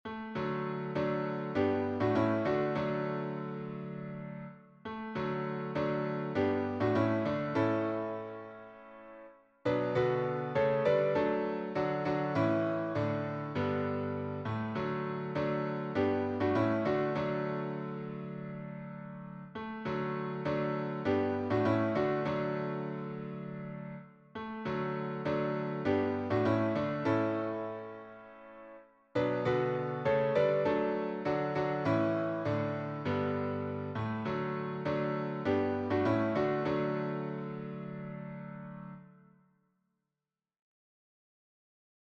A cappella
SATB